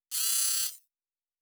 Servo Small 9_2.wav